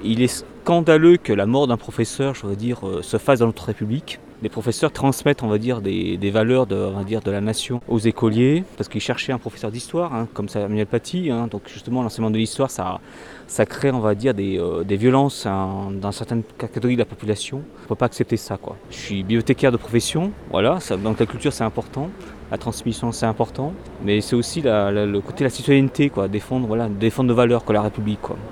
Une cinquantaine de personnes s’étaient rassemblées.